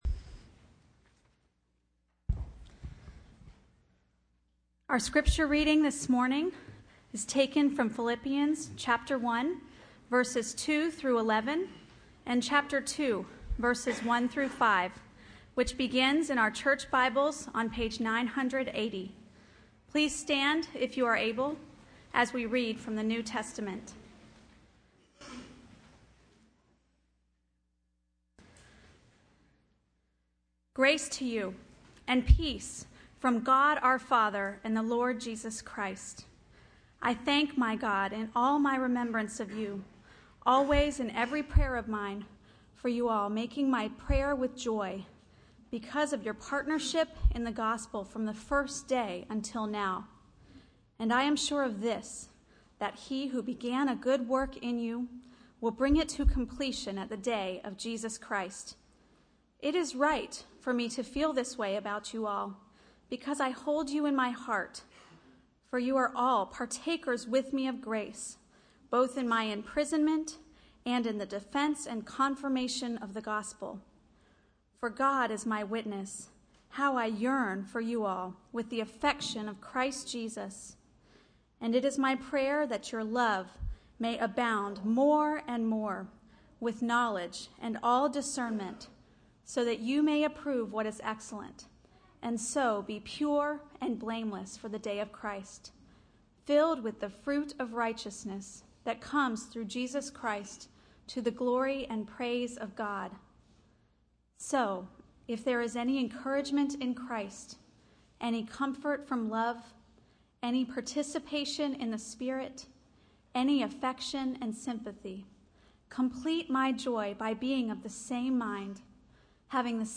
sermon-audio-8.18.13.mp3